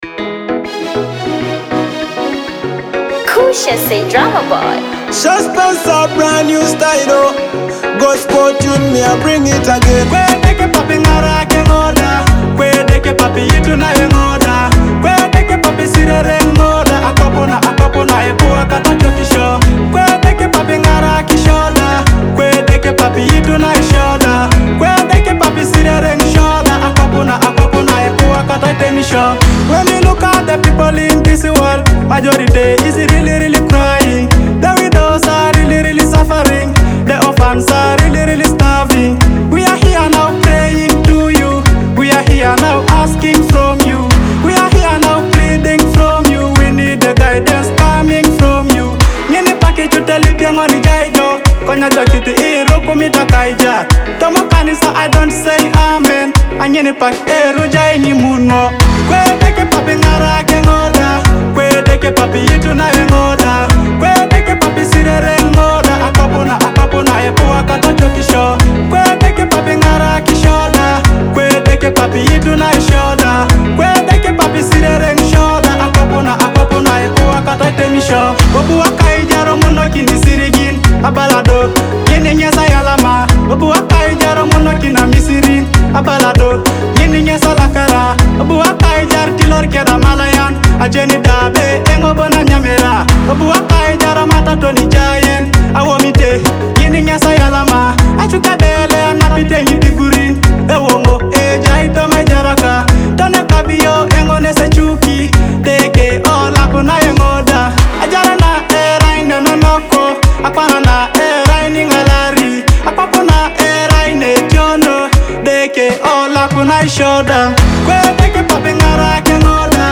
With stirring vocals and powerful lyrics